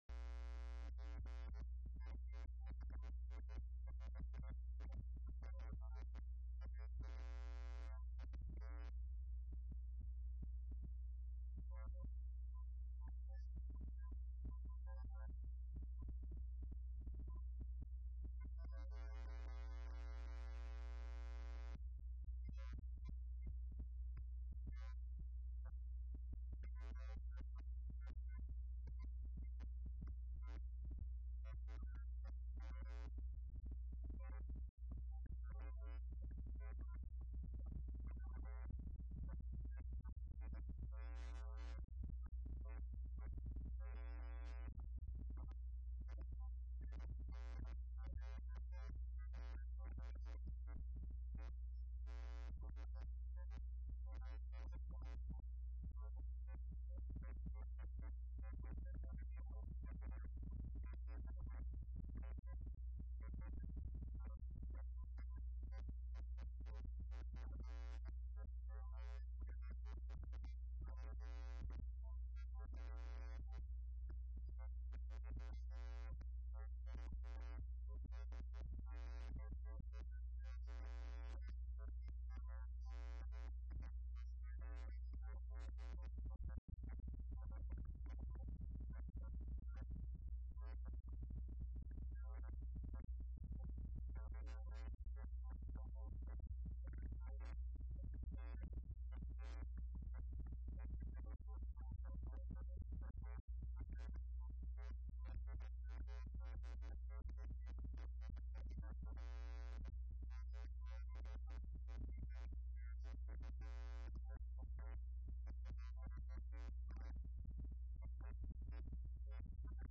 interview.wma